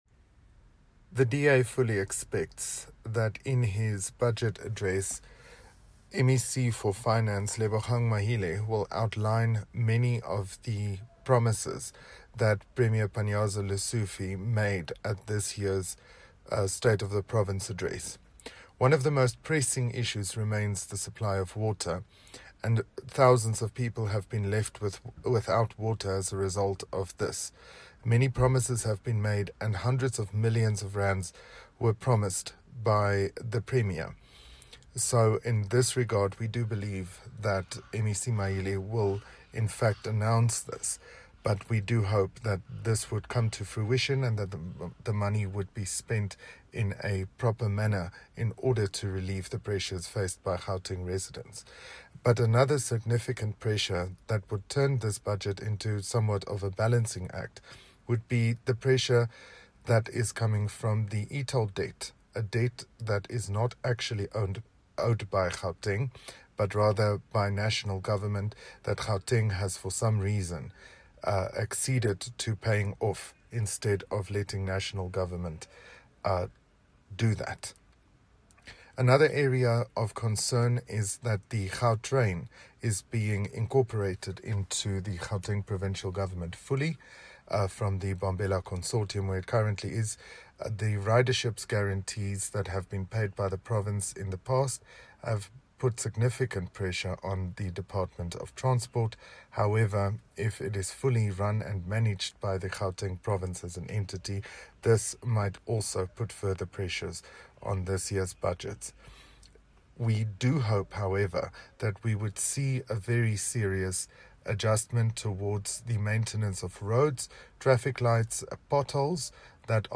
soundbite by Ruhan Robinson MPL.